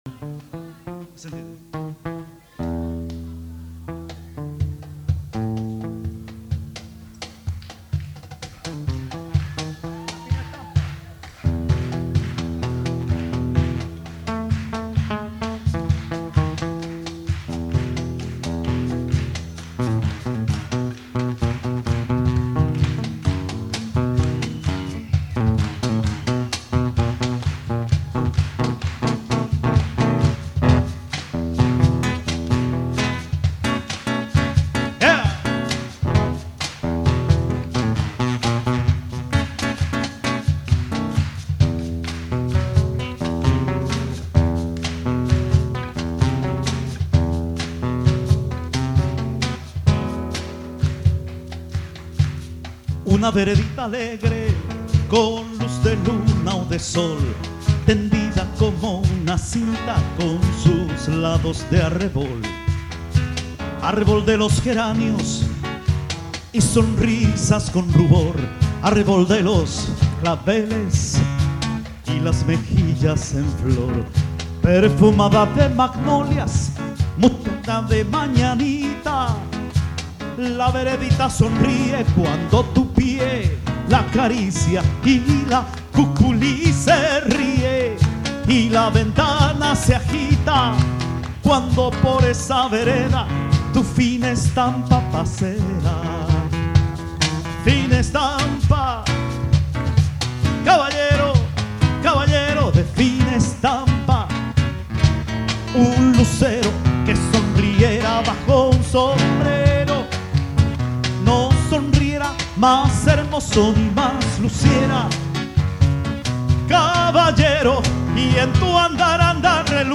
voce, chitarra, tiple, charango
sax tenore e soprano. flauti
Registrato a Milano - CSOA Leoncavallo il 7 marzo 2000